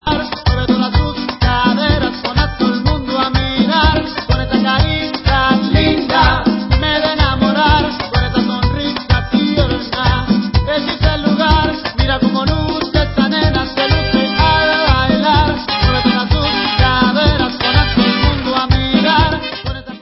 sledovat novinky v oddělení World/Latin